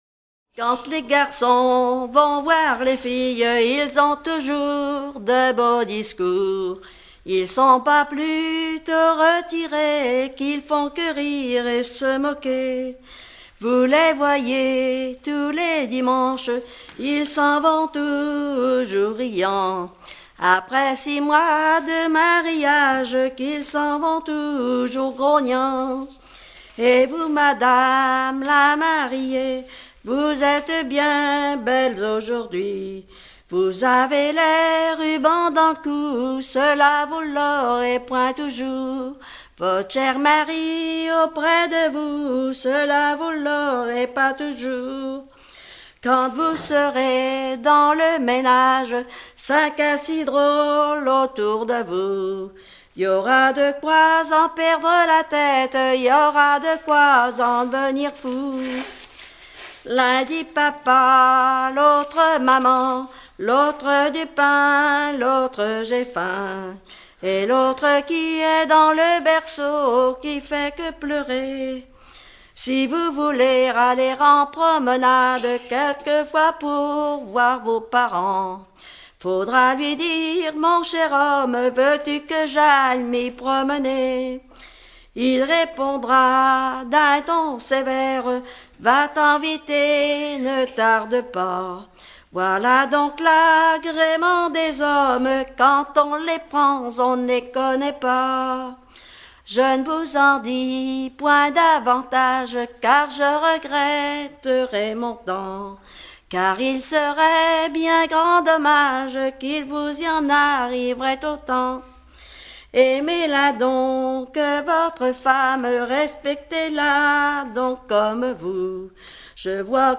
circonstance : fiançaille, noce